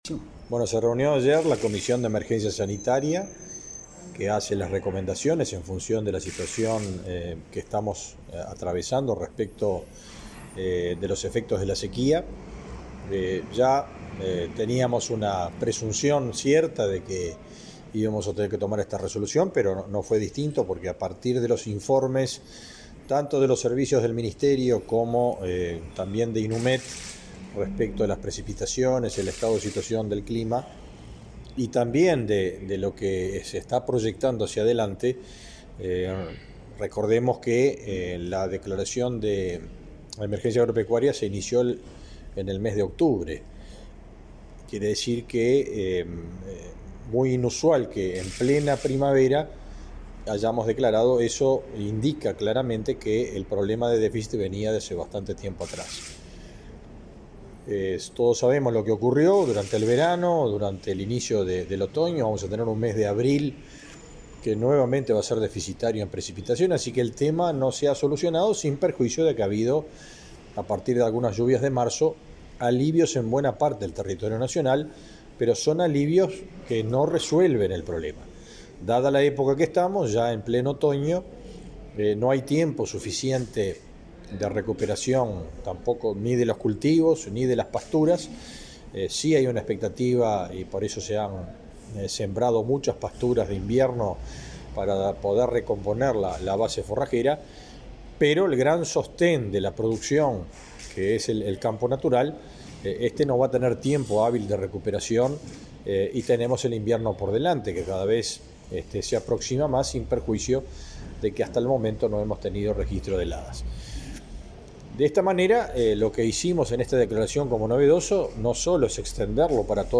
Entrevista al ministro de Ganadería, Fernando Mattos
El ministro de Ganadería, Agricultura y Pesca, Fernando Mattos, dialogó con Comunicación Presidencial acerca de la extensión de la emergencia